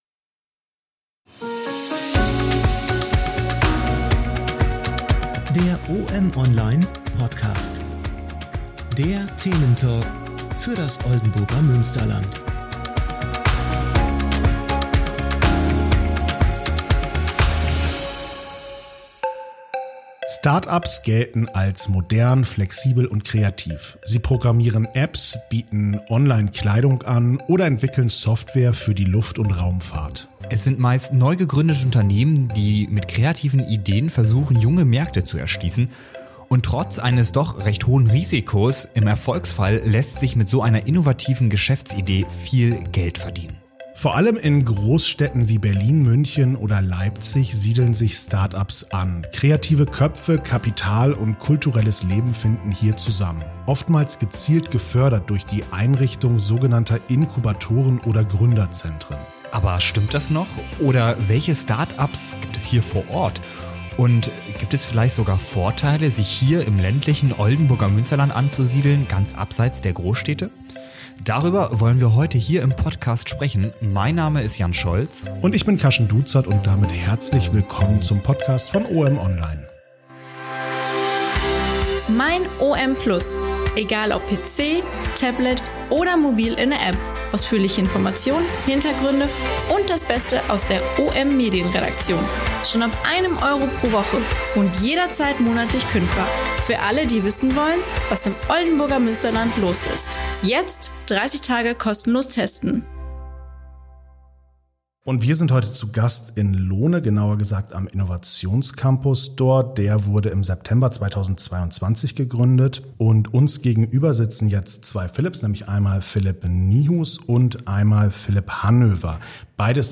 Welche Start-ups gibt es hier vor Ort? Und gibt es vielleicht sogar Vorteile, im eher ländlichen Oldenburger Münsterland und abseits von Großstädten ein solches Unternehmen zu gründen? Über diese und weiter Fragen diskutieren die Moderatoren